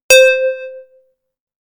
D o e p f e r    A - 100
Bell 1 C
Bell1-C.mp3